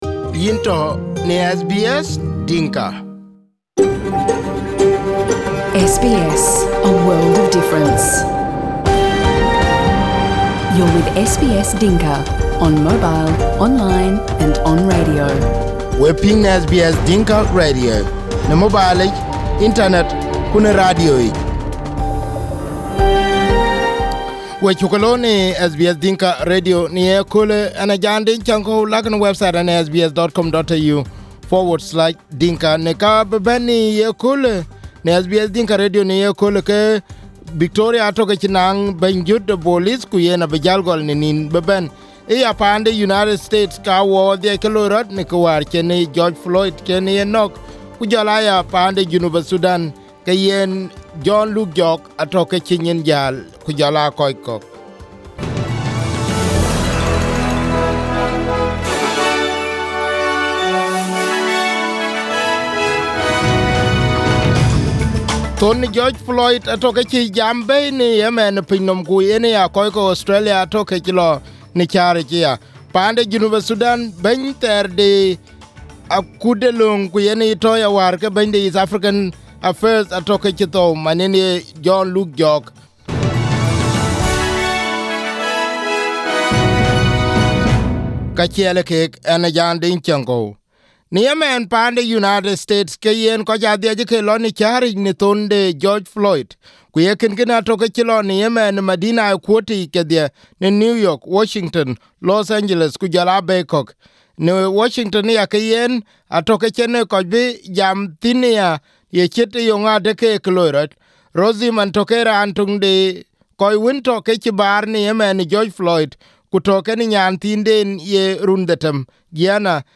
SBS Dinka News - 3/06/2020 11:00:00 AM